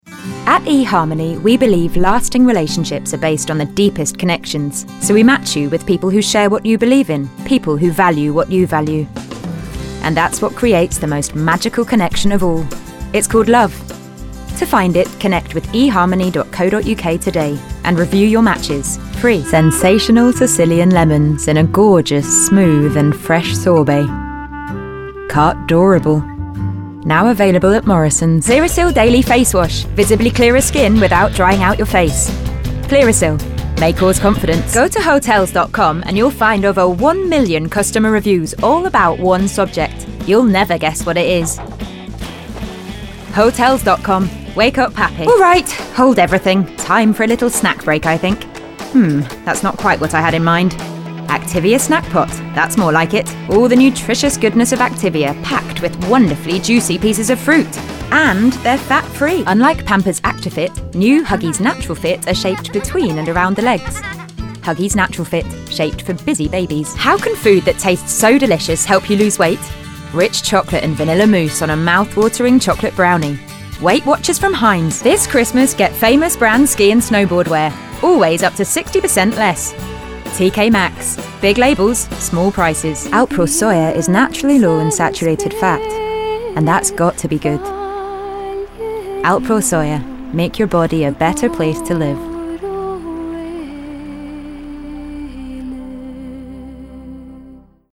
English (British)
Warm
Friendly
Calm